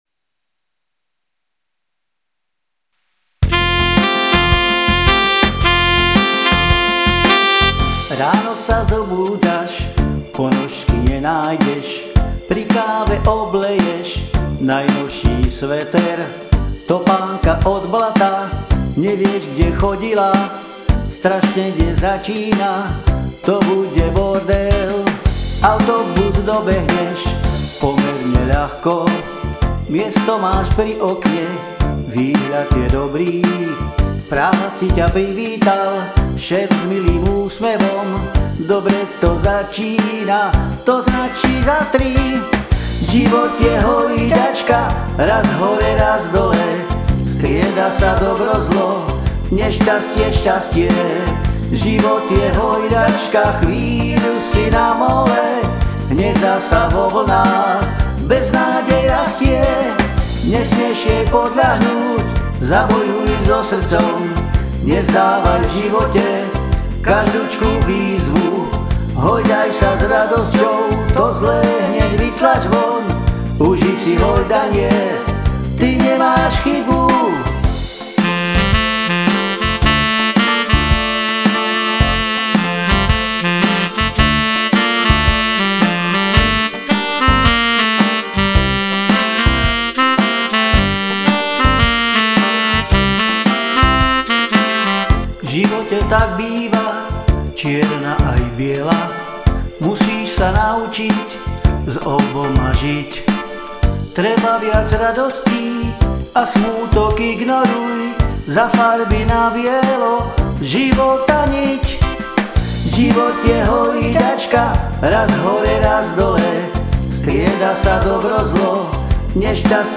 Záhorácke ľudové piesne - Piesne POP - HOJDAČKA
Som amatérsky muzikant, skladám piesne väčšinou v "záhoráčtine" a tu ich budem prezentovať.